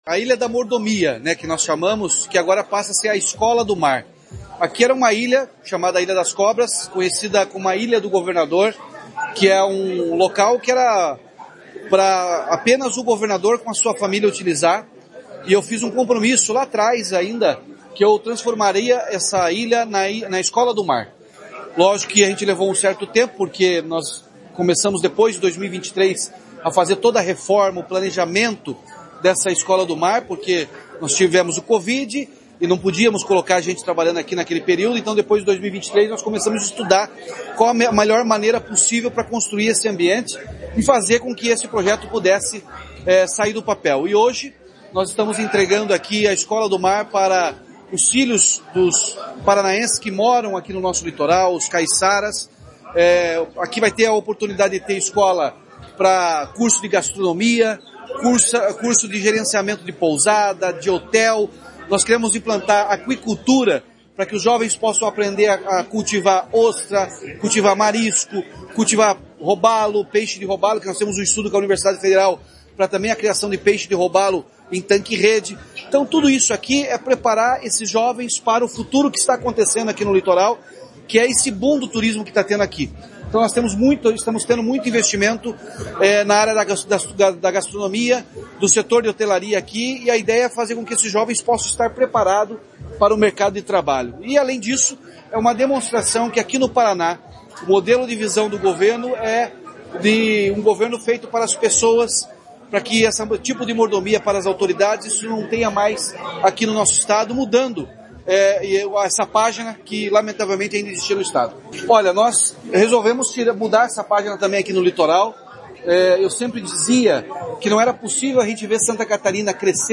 Sonora do governador Ratinho Junior sobre a Escola do Mar no Litoral